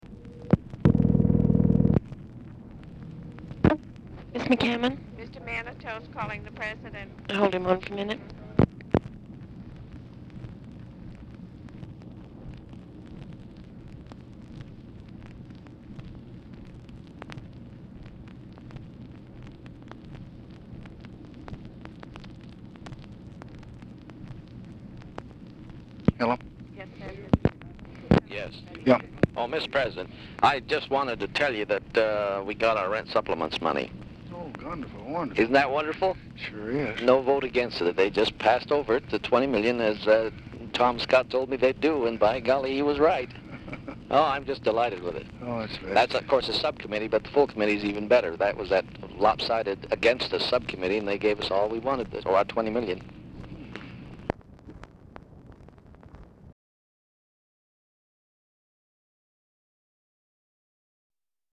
Telephone conversation # 10541, sound recording, LBJ and MIKE MANATOS
MANATOS ON HOLD 0:28; RECORDING ENDS BEFORE CONVERSATION IS OVER
Format Dictation belt
Location Of Speaker 1 Oval Office or unknown location